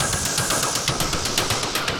Index of /musicradar/rhythmic-inspiration-samples/120bpm
RI_DelayStack_120-05.wav